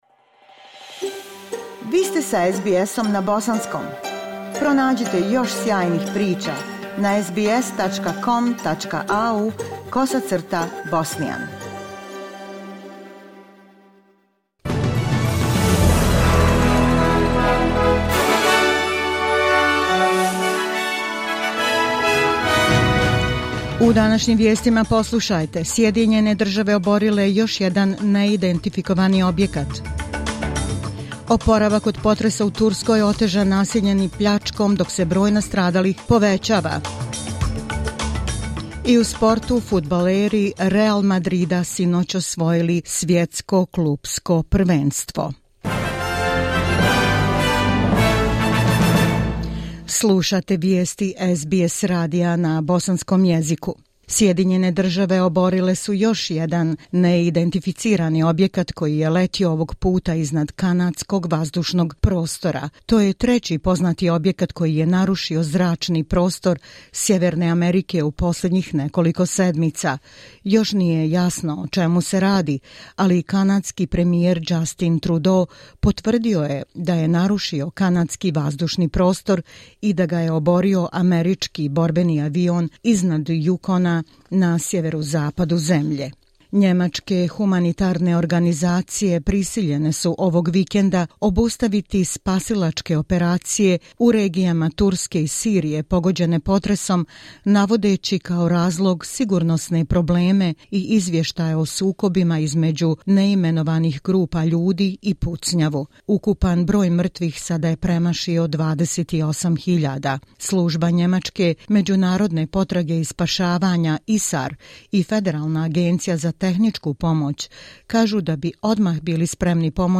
Vijesti SBS radija na bosanskom jeziku.